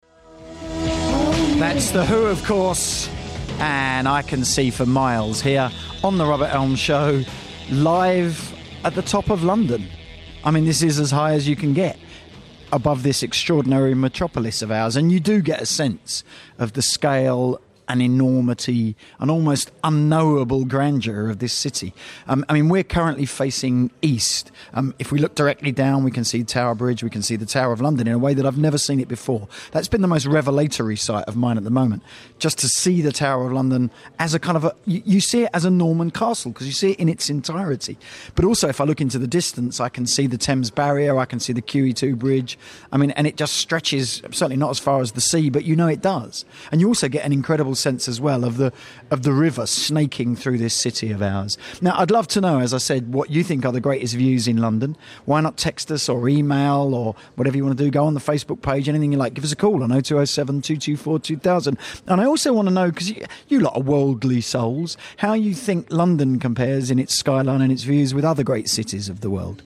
Live from The Shard